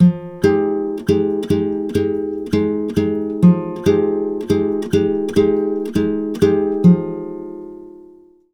140GTR FM7 4.wav